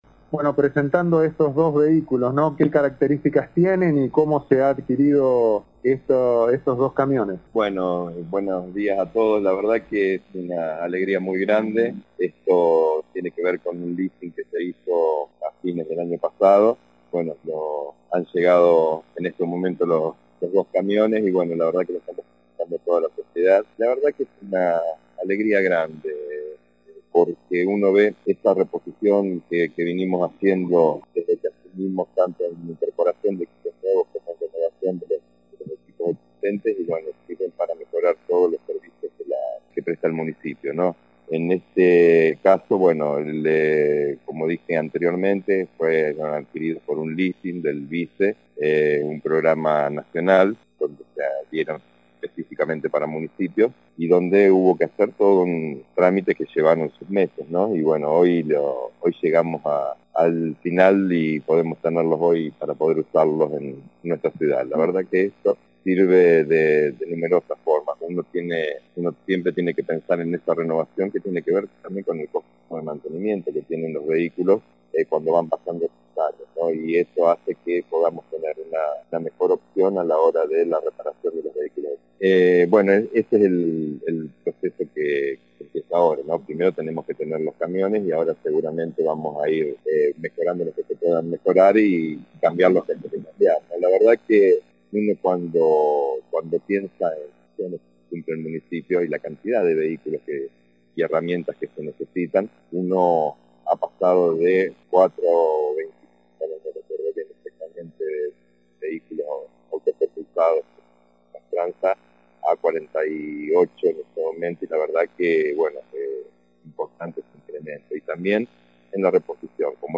En la mañana de hoy nuestro móvil tomaba contacto con el intendente de la ciudad el Dr. Pablo Verdecchia, donde nos contaba que han realizado las gestiones necesarias para mejorar los servicios públicos que se traducen en más higiene urbana.